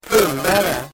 Uttal: [²p'un:dare]